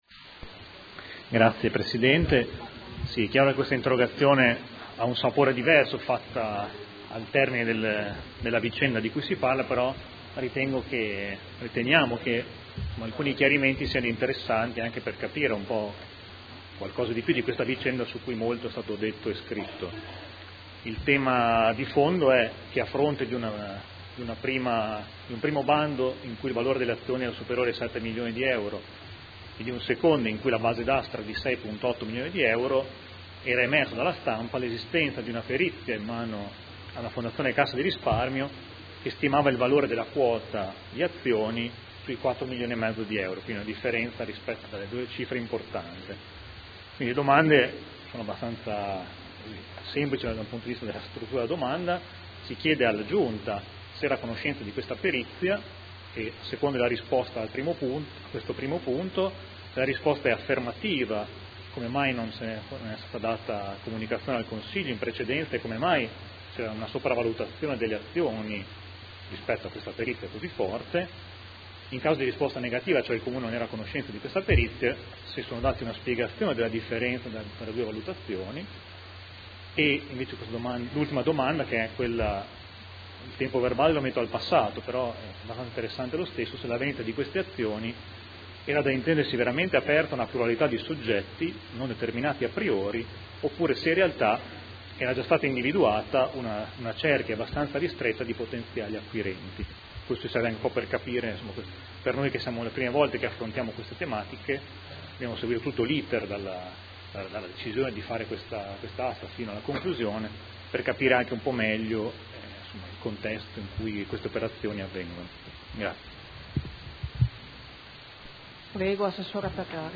Seduta del 22 ottobre. Interrogazione del Gruppo Consiliare Movimento 5 Stelle avente per oggetto: Informazioni a corredo del bando di vendita azioni Farmacie Comunali